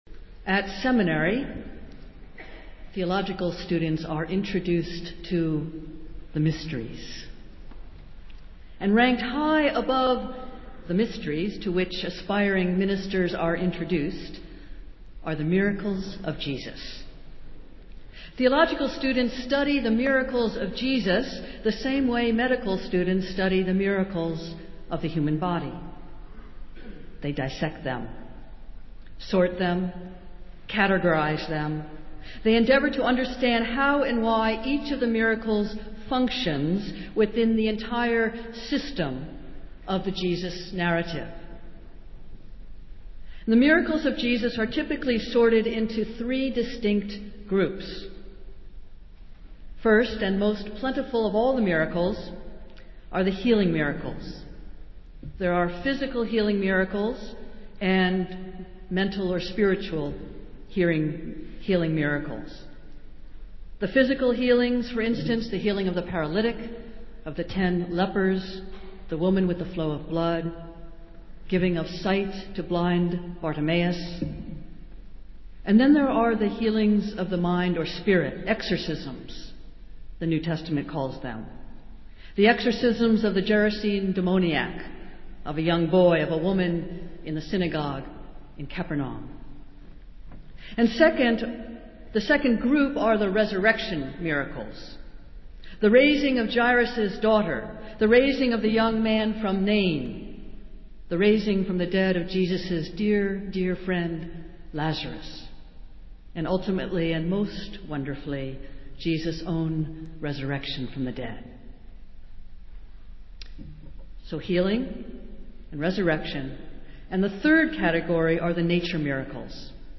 Festival Worship - Fourth Sunday after Epiphany